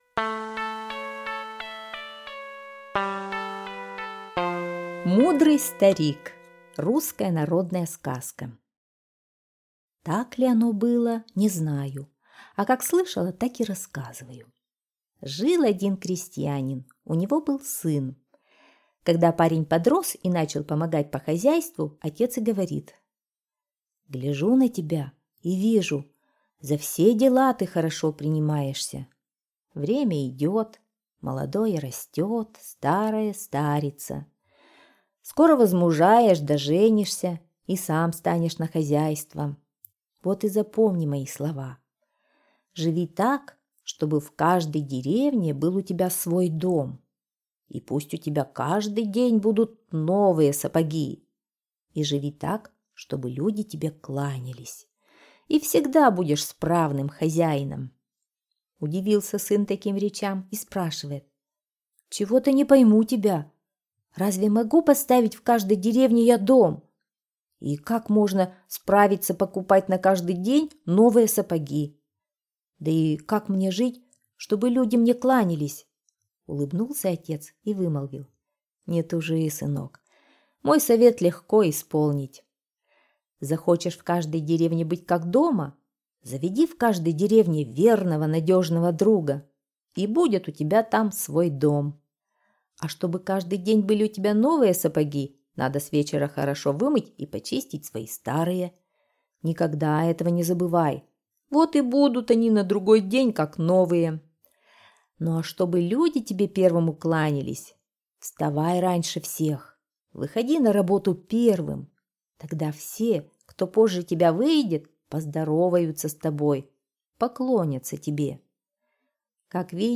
Мудрый старик — русская народная аудиосказка. Сказка про крестьянина, который дал мудрый совет своему повзрослевшему сыну.